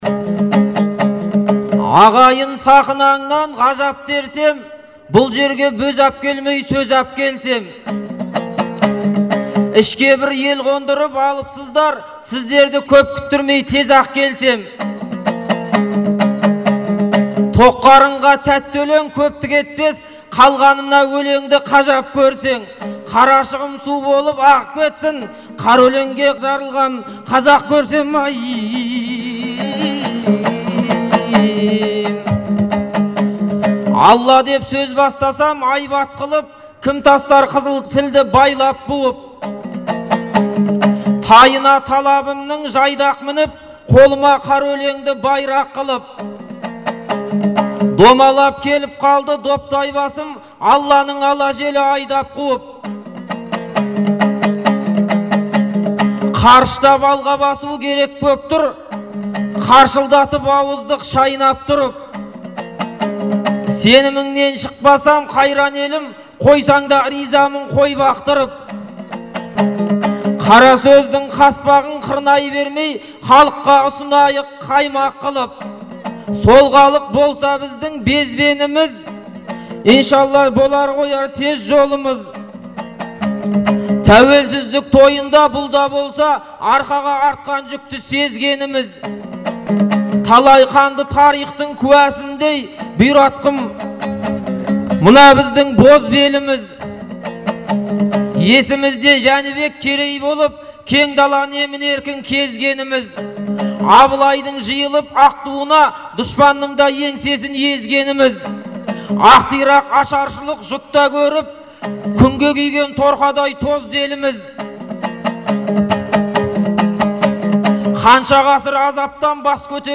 айтысы